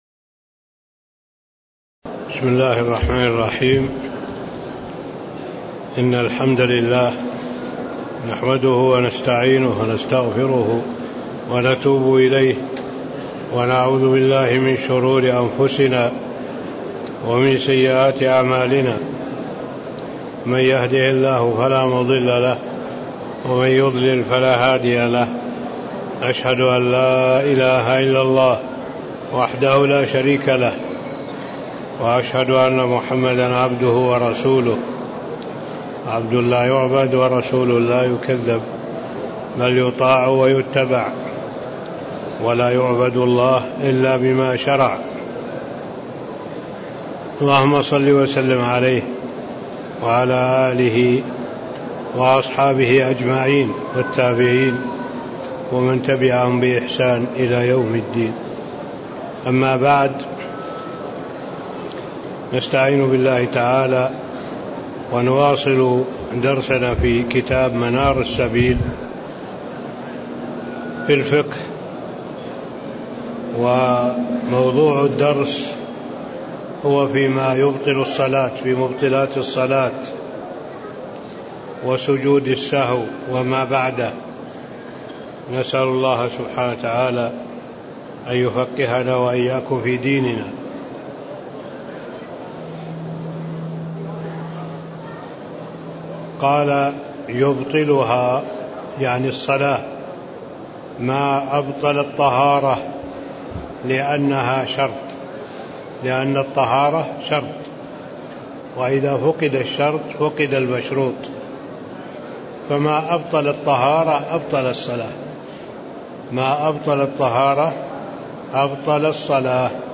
تاريخ النشر ٣٠ جمادى الآخرة ١٤٣٦ هـ المكان: المسجد النبوي الشيخ